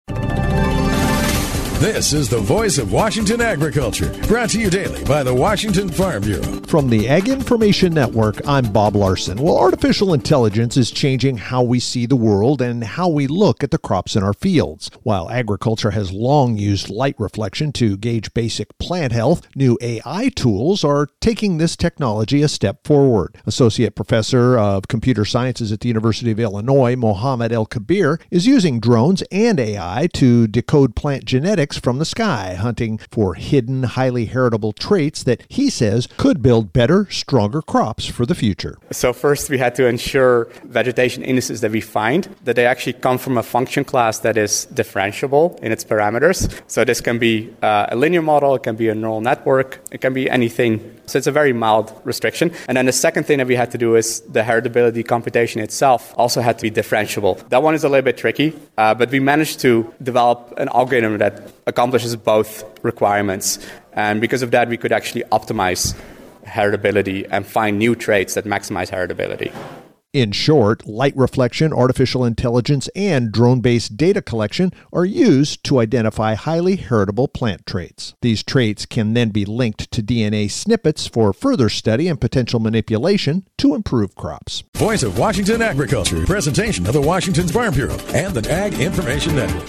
Friday Mar 27th, 2026 38 Views Washington State Farm Bureau Report